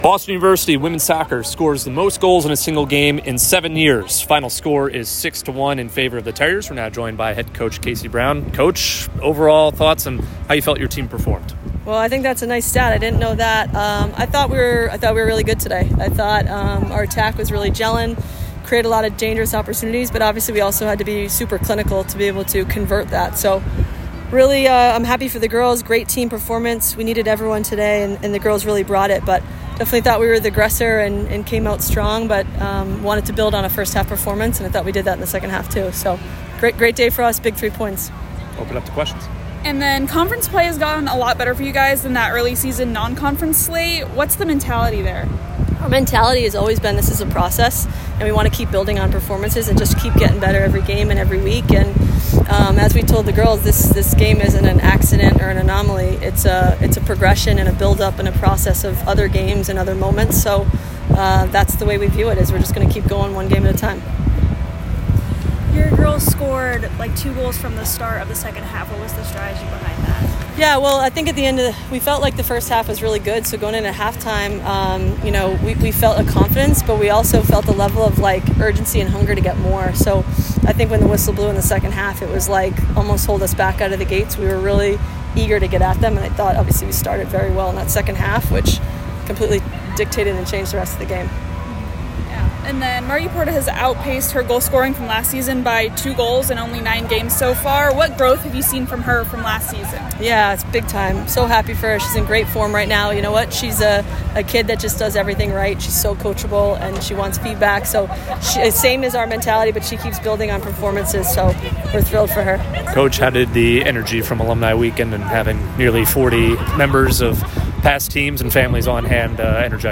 Women's Soccer / Lehigh Postgame Interview